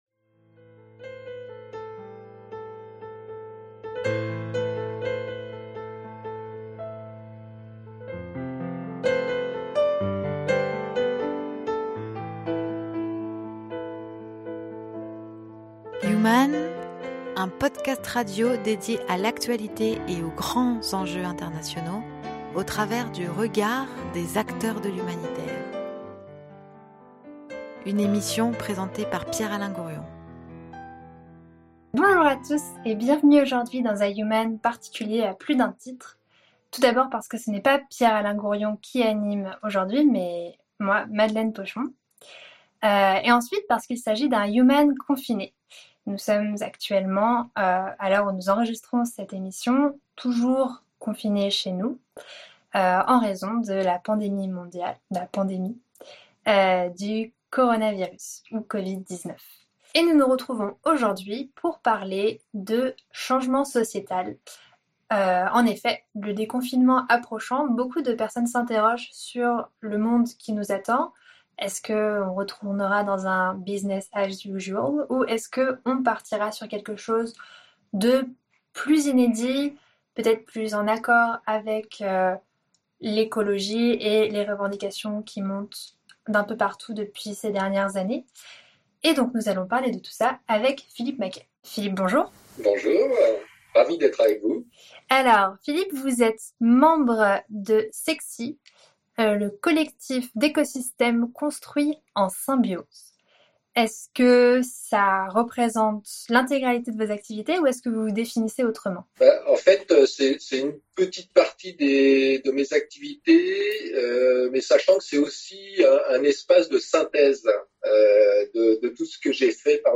Entretien confiné
Emission réalisée en période de confinement